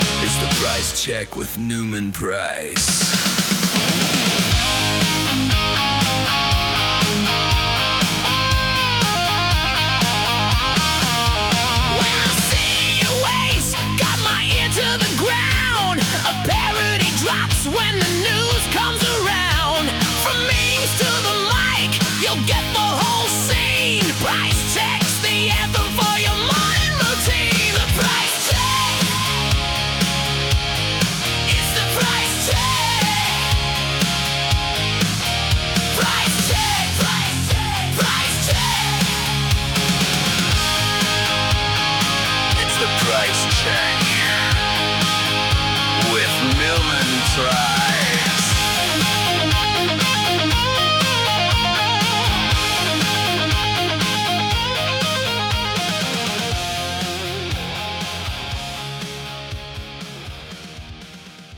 Catchy hook.